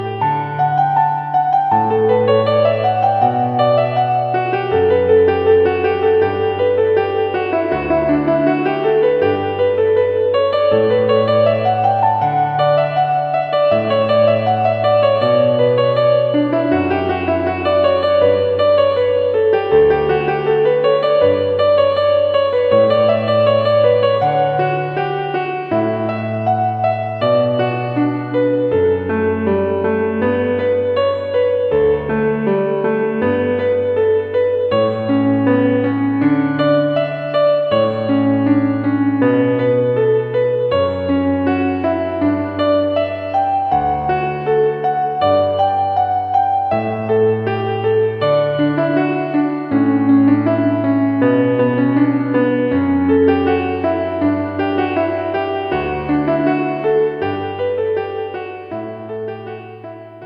メロディー チャイム「カノン」
今週より、授業始まりのチャイムがメロディーに変わりました。優雅な音楽が流れています。
一つのメロディーを追いかけるようにして演奏していく演奏様式の事だそうです。